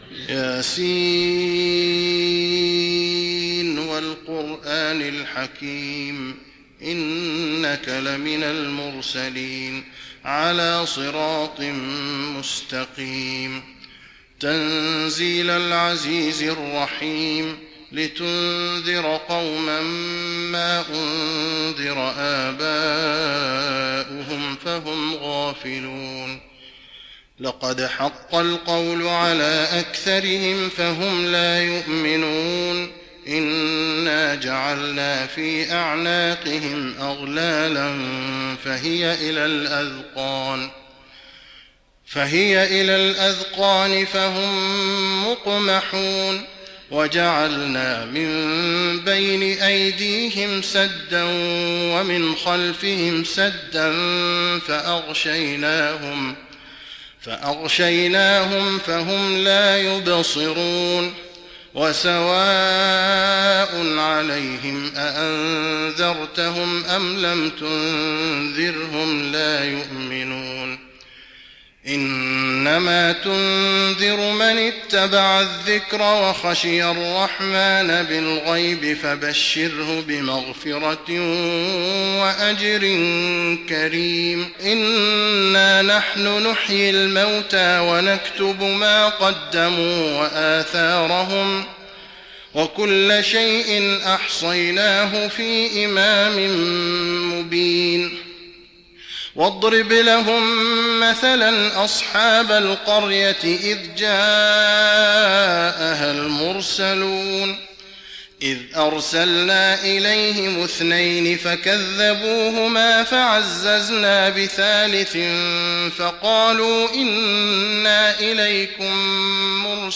تراويح رمضان 1415هـ من سورة يس الى الصافات (1-113) Taraweeh Ramadan 1415H from Surah Yaseen and As-Saaffaat > تراويح الحرم النبوي عام 1415 🕌 > التراويح - تلاوات الحرمين